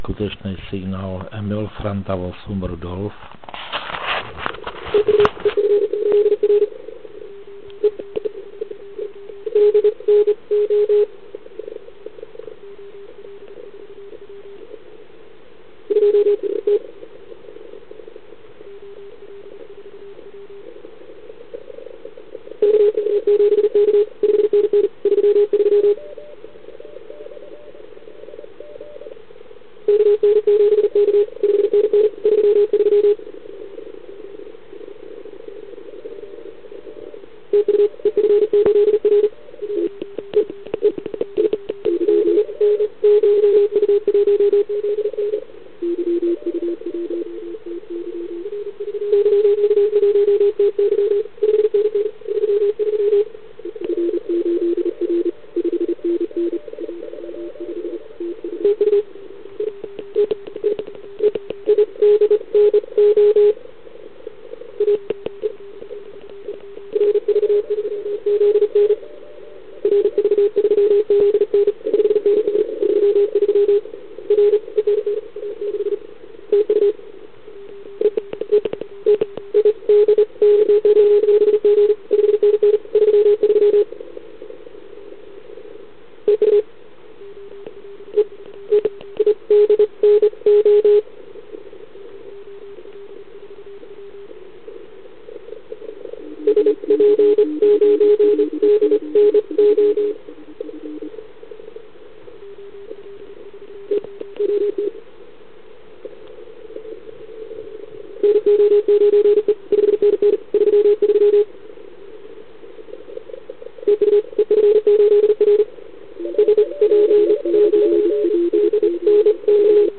Ale pojďme se zaposlouchat do pár signálků.